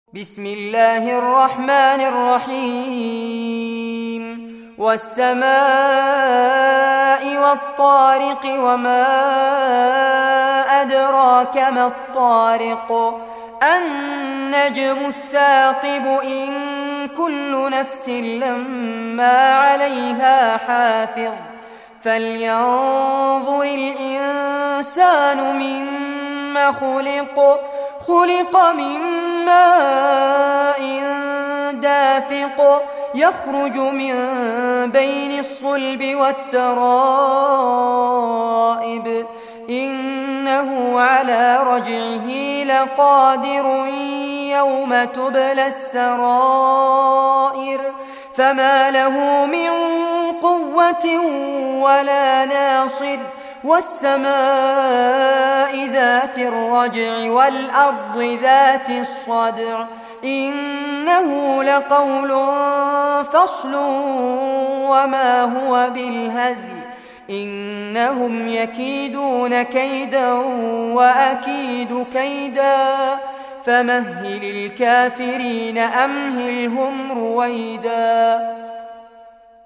Surah At-Tariq, listen or play online mp3 tilawat / recitation in Arabic in the beautiful voice of Sheikh Fares Abbad.